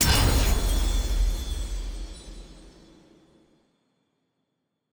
sfx-exalted-hub-summon-x1-click.ogg